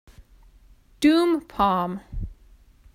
(dm päm)